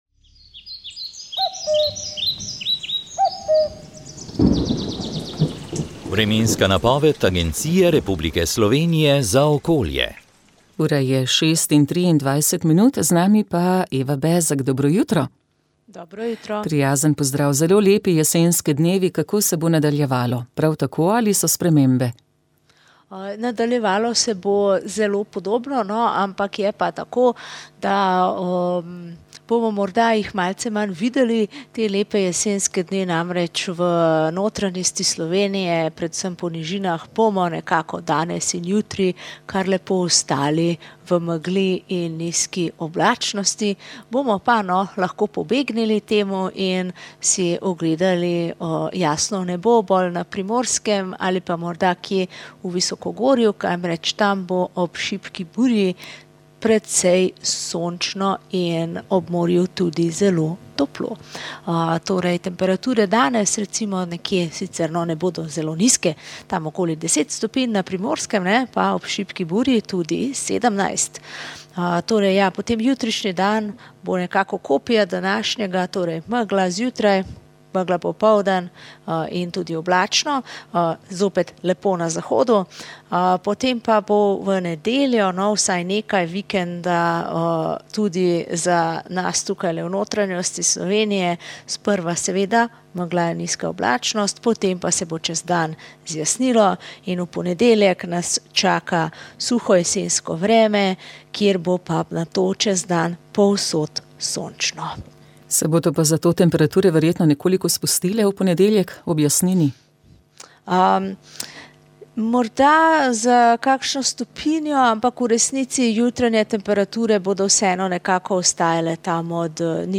Sveta maša
Prenos svete maše iz bazilike Marije Pomagaj na Brezjah dne 18. 5